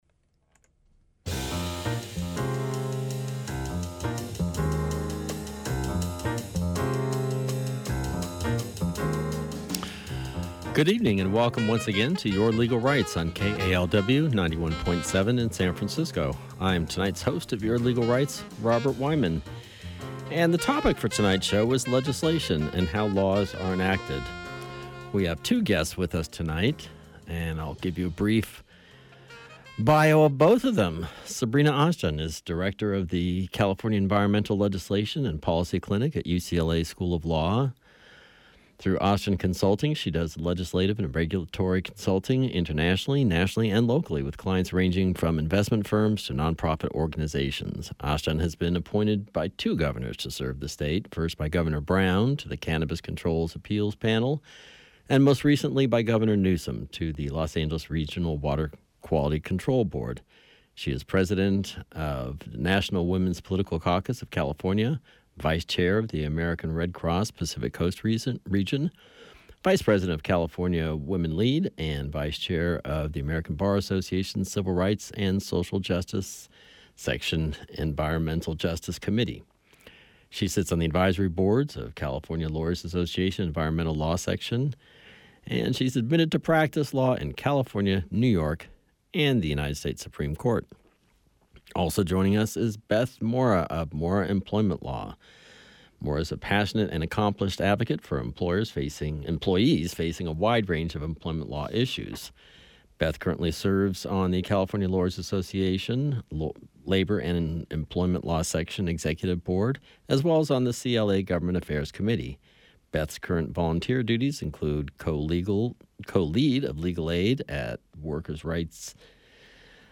talks with experts on various legal topics, with listener participation